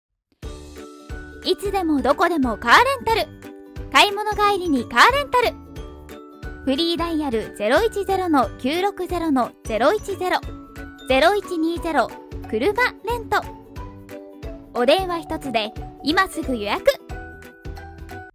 丁寧・高品質・リーズナブルなプロの女性ナレーターによるナレーション収録
株主総会のナレーション